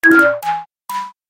Звуки разряженной батареи
Аккумулятор сдох